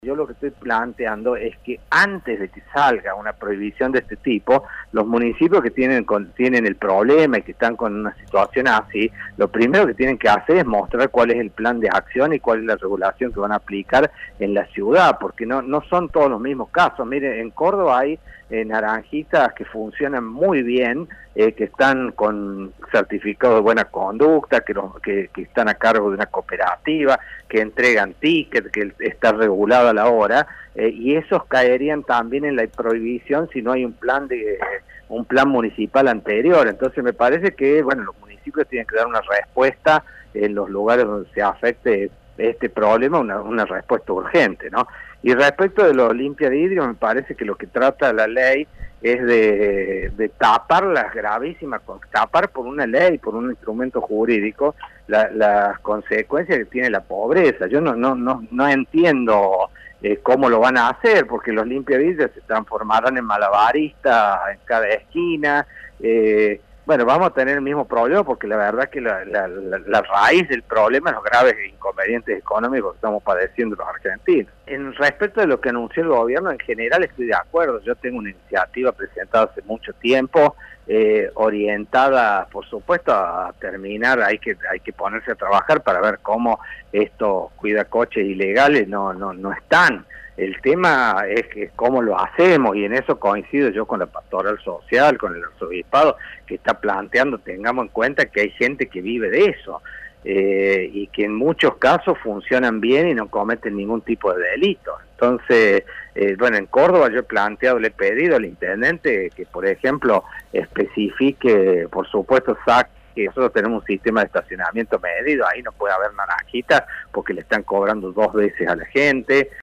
El legislador radical, Dante Rossi, indicó que antes que una normativa provincial es necesario que cada municipio elabore sus ordenanzas.